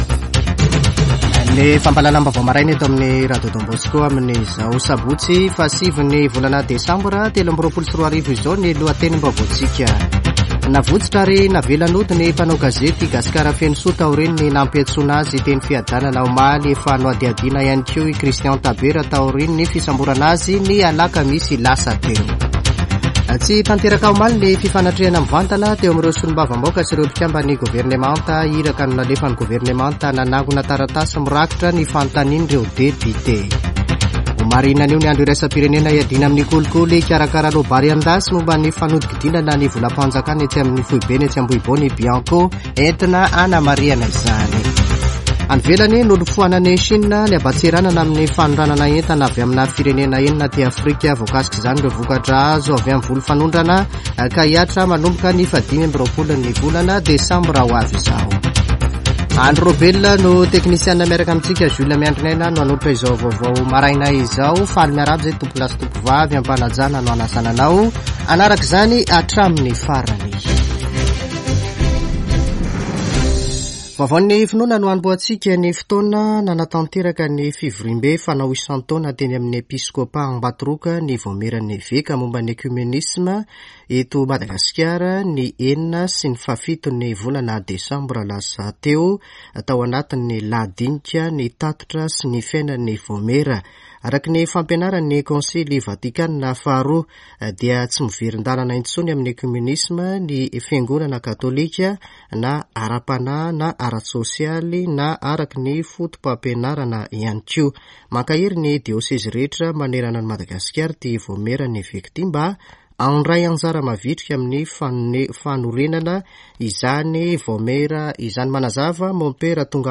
[Vaovao maraina] Sabotsy 9 desambra 2023